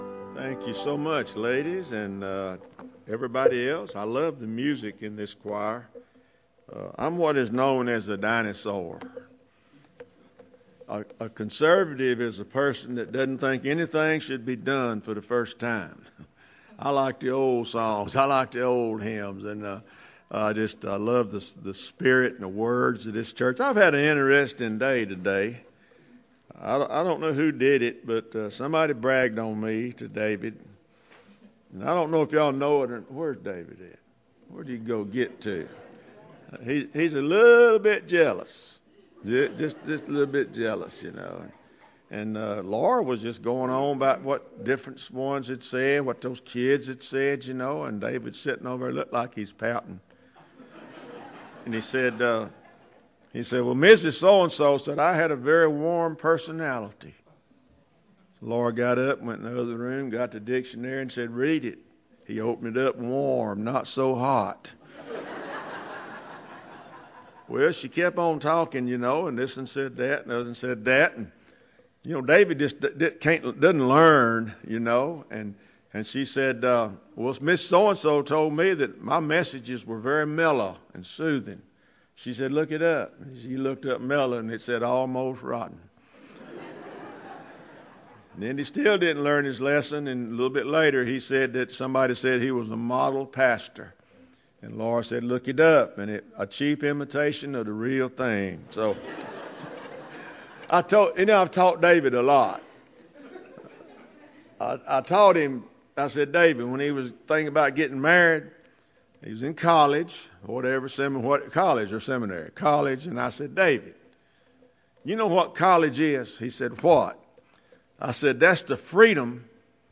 Fall 2015 Revival Sunday Morning